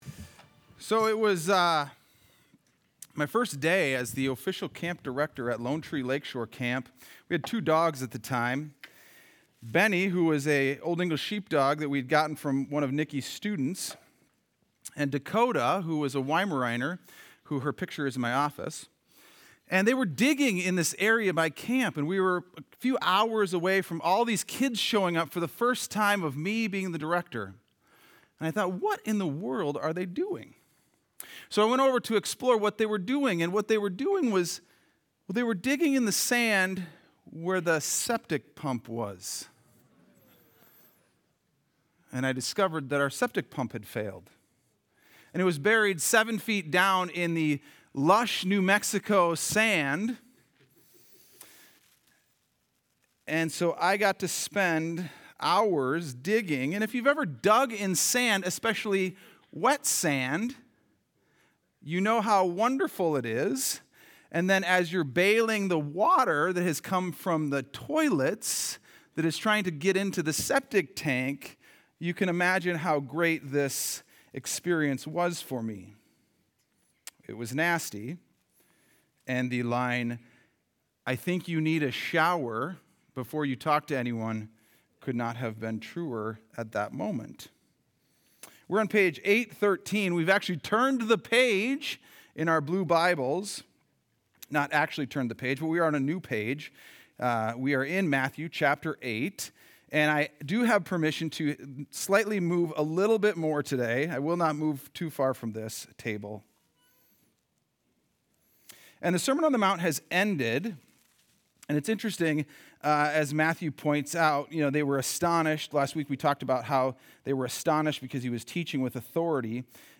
Sunday Sermon: 10-19-25